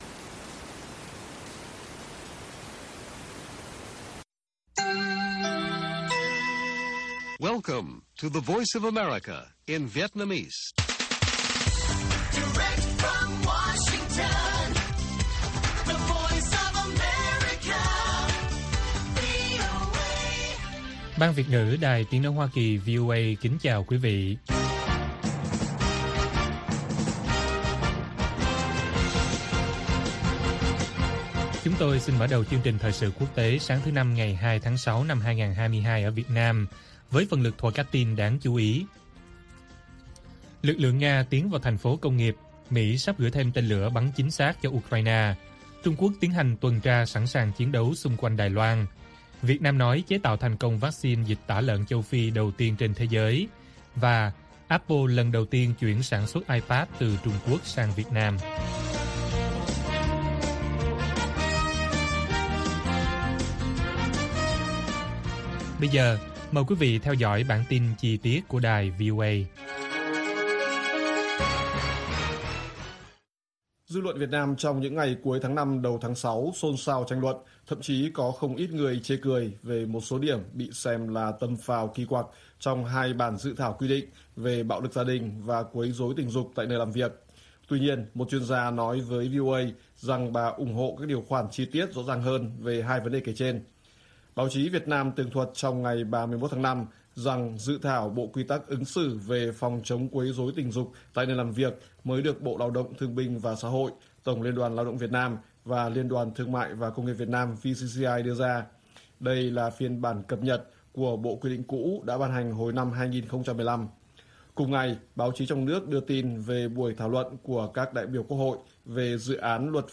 Lực lượng Nga tiến vào thành phố công nghiệp ở miền đông Ukraine - Bản tin VOA